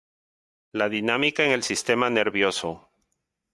sis‧te‧ma
/sisˈtema/